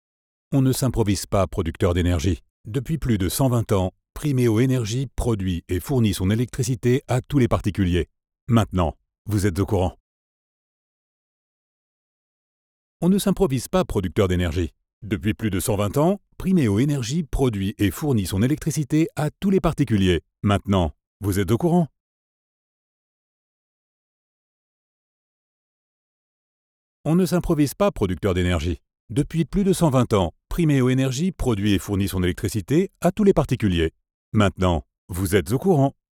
Voix off
30 - 50 ans - Basse